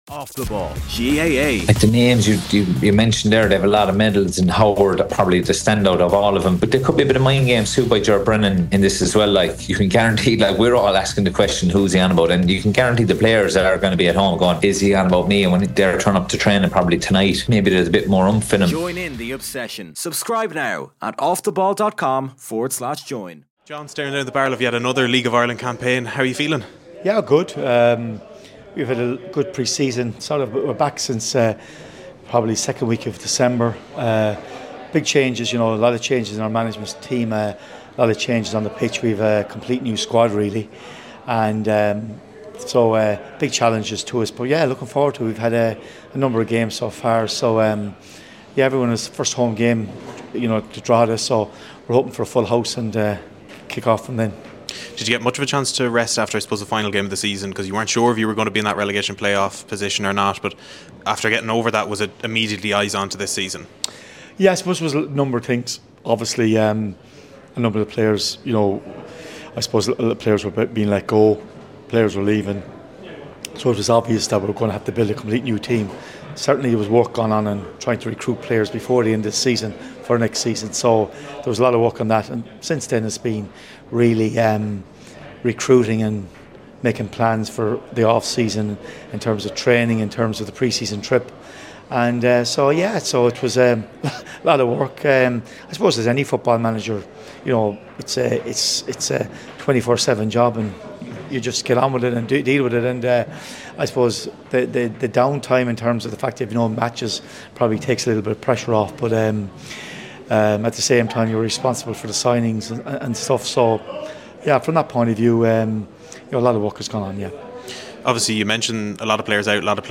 at the launch at Whelans